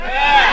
TM88 RevsVox.wav